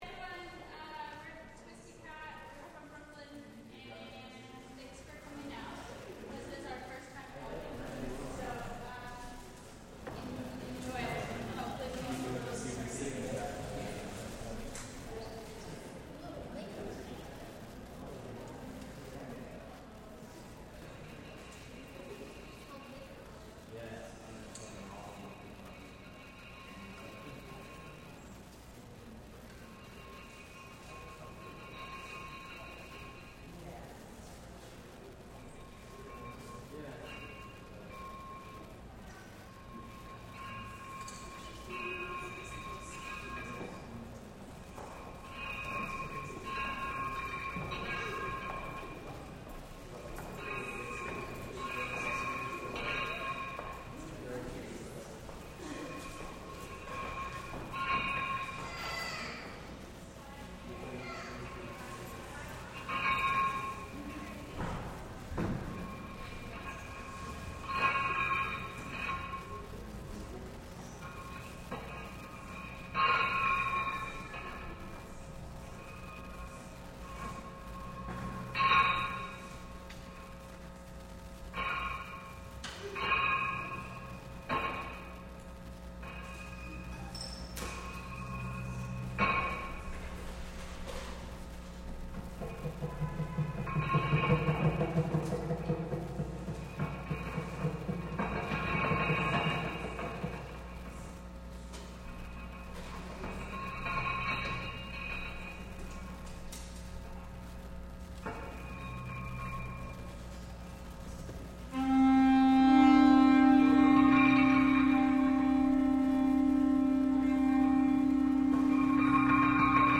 ((audience))